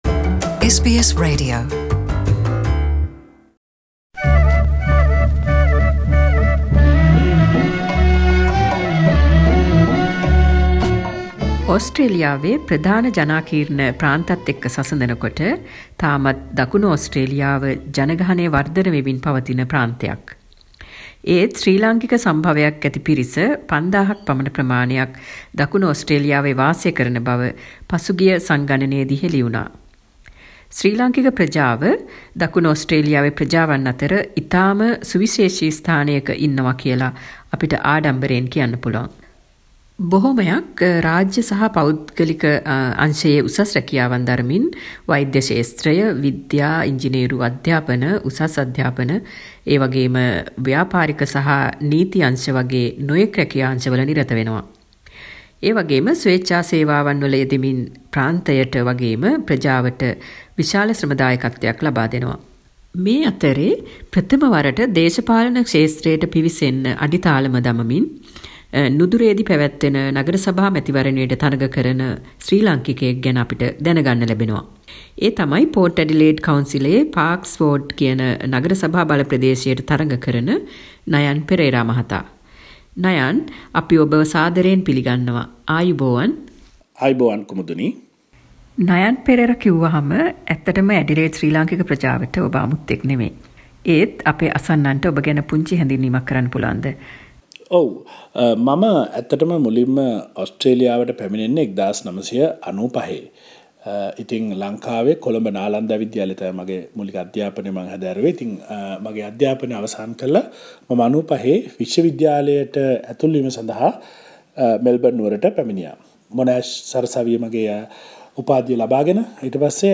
සාකච්චාවක්